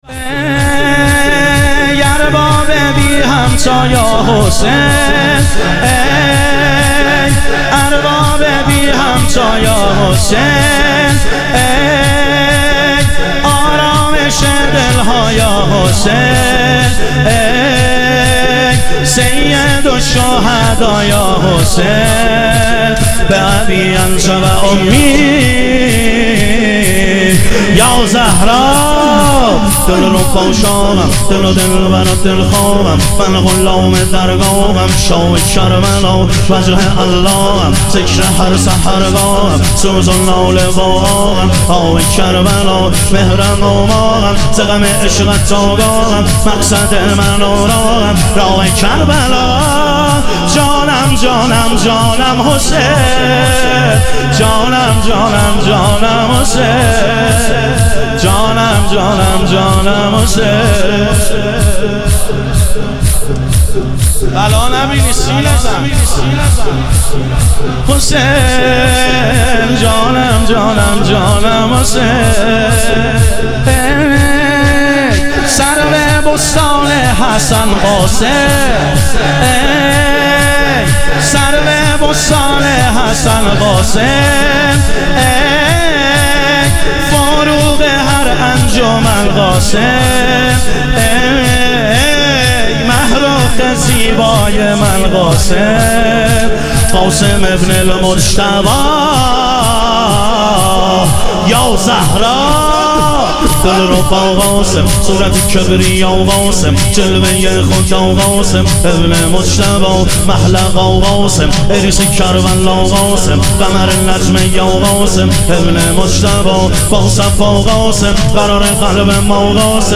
ایام فاطمیه اول - شور